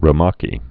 (rə-mäkē)